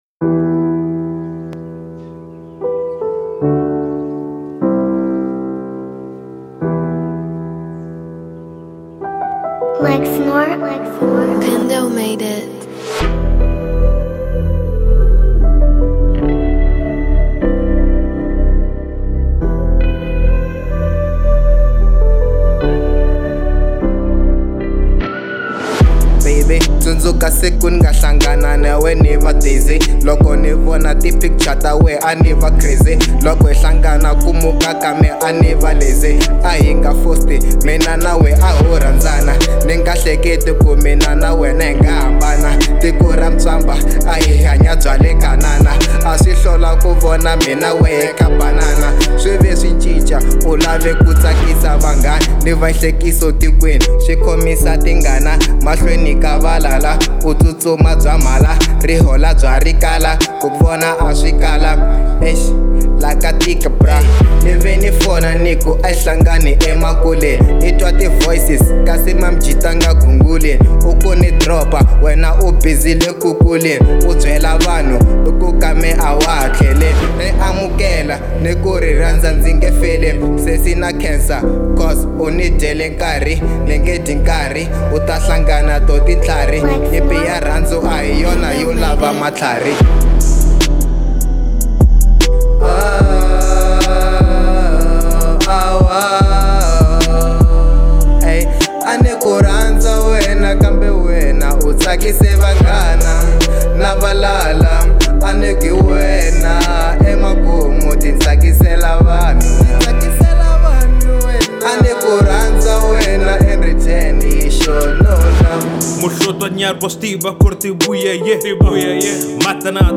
03:22 Genre : Hip Hop Size